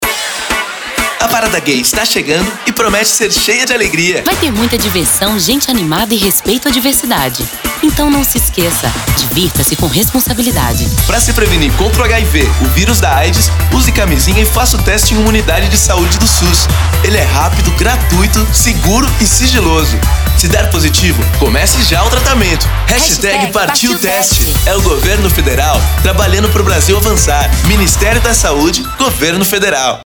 Spot Parada Gay (MP3, 1.15 MB)1.15 MB
spot_parada_gay_op1.mp3